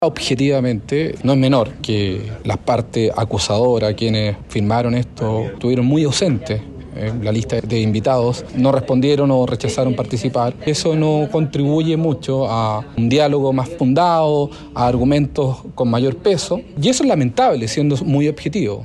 La lista de testigos, de invitados, no respondieron o rechazaron participar”, cuestionó el parlamentario de RN.